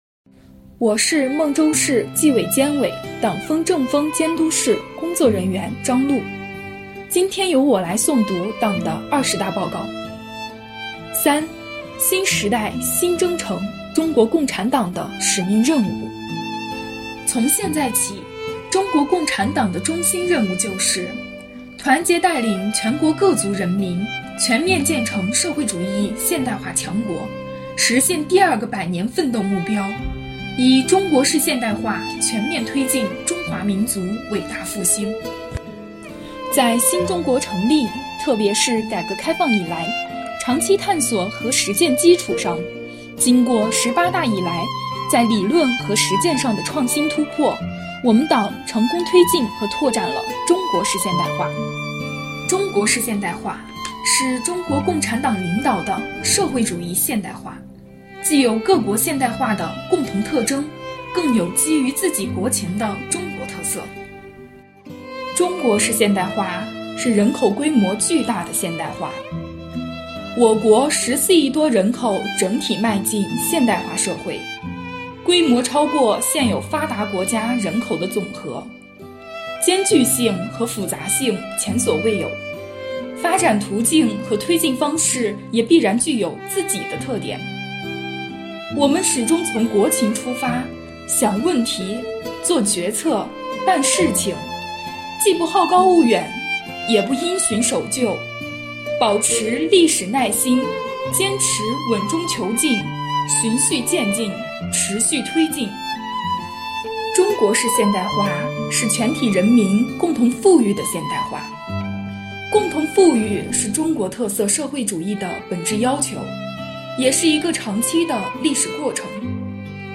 诵读二十大报告第三部分（上）.mp3
本期诵读人
诵读内容